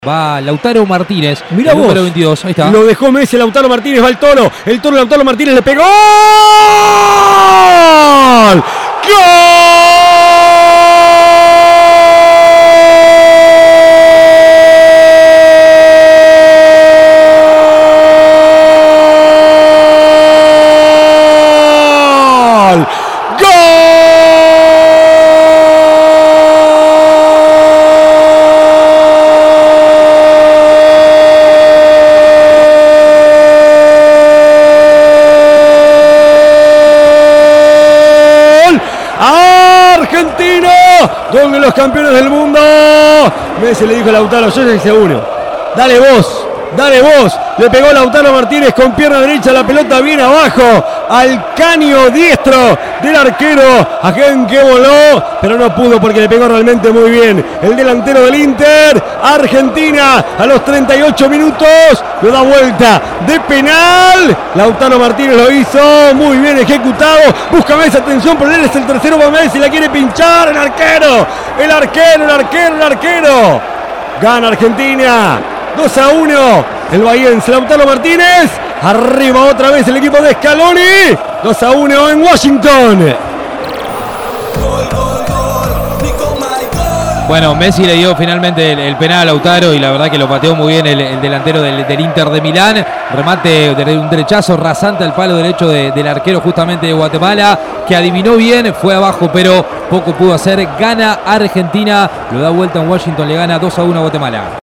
Lionel Messi empata el duelo ante Guatemala (Relatos